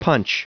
Prononciation du mot punch en anglais (fichier audio)
Prononciation du mot : punch